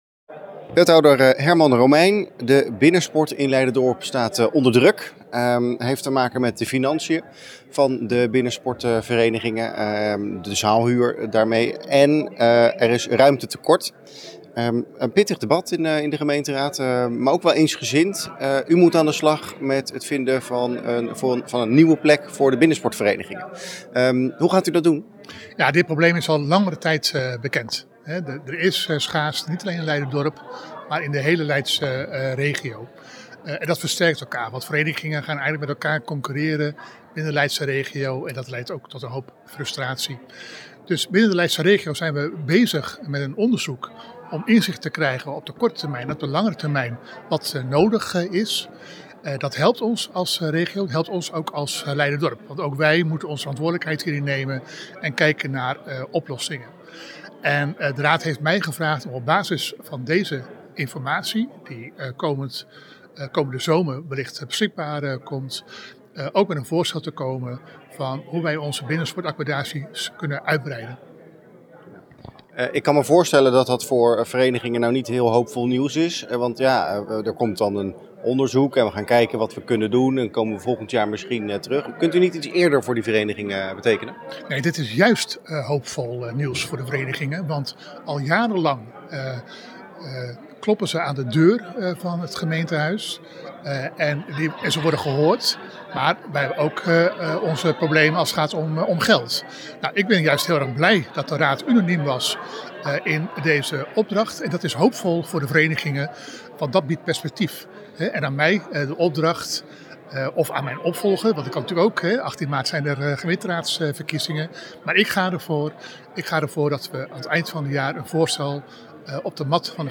Wethouder Herman Romeijn na afloop van de vergadering over de binnensport: